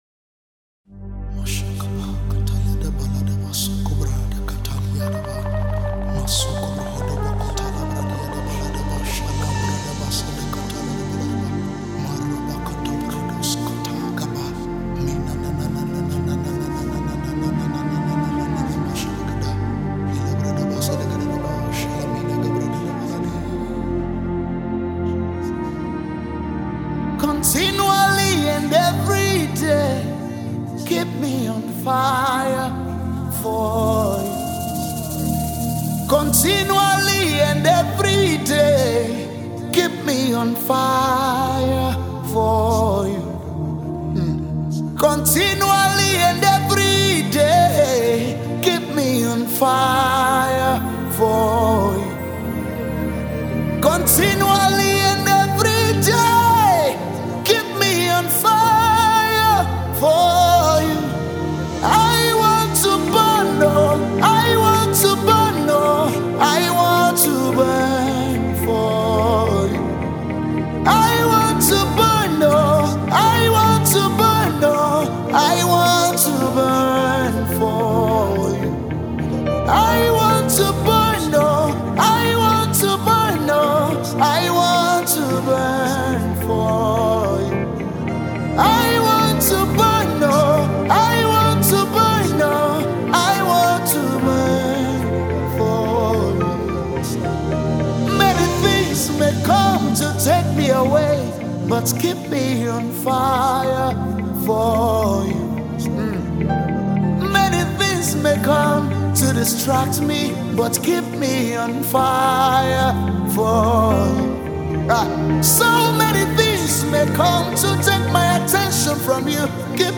Spirit Filled Worship Song